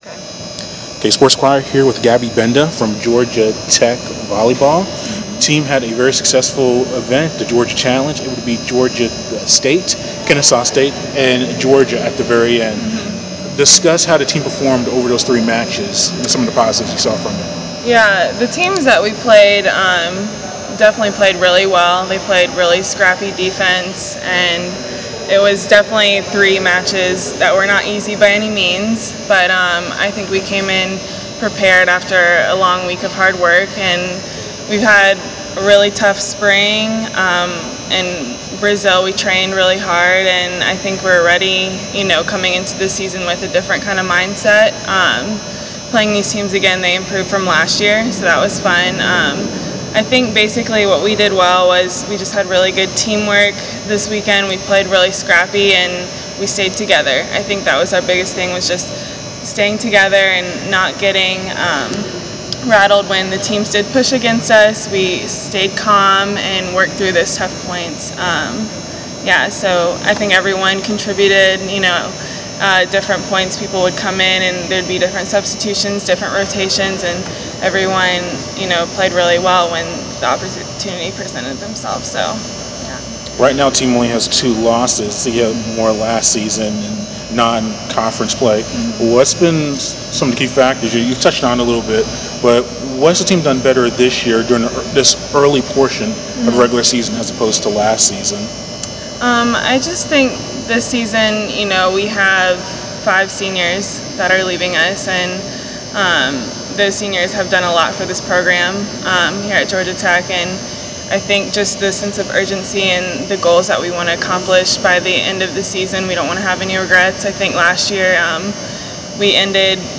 interview
Georgia Tech volleyball player